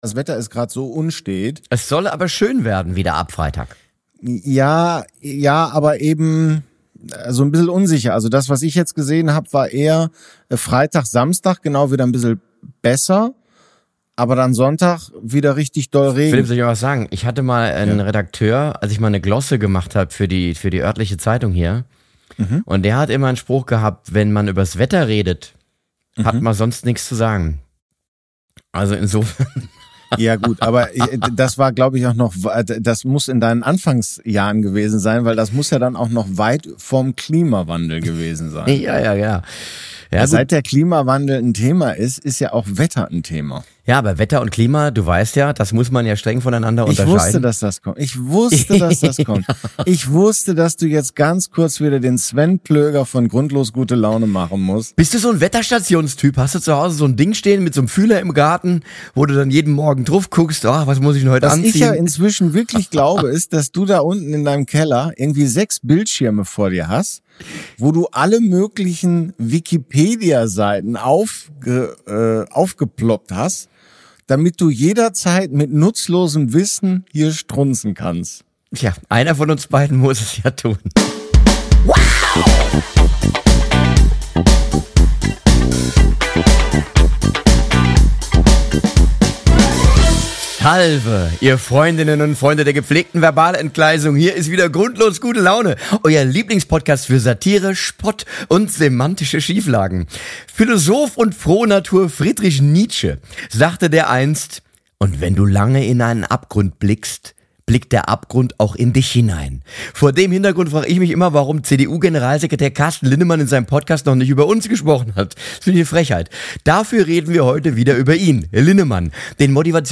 Die beiden Kabarettisten sind seit vielen Jahren befreundet und quatschen eh ständig über Gott und die Welt.